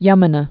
(yŭmə-nə) or Jum·na (jŭmnə)